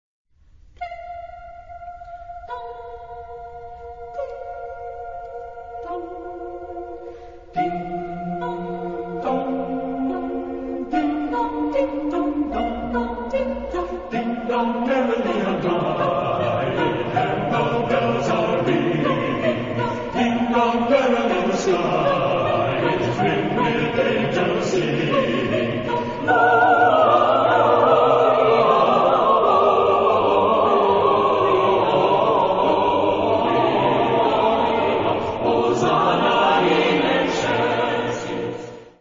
Mood of the piece: joyous
Type of Choir: SATB div.  (4 mixed voices )
Tonality: major